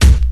Tuned drums (B key) Free sound effects and audio clips
• 80's Good Steel Kick Drum Sample B Key 47.wav
Royality free kick sound tuned to the B note. Loudest frequency: 594Hz
80s-good-steel-kick-drum-sample-b-key-47-mN5.wav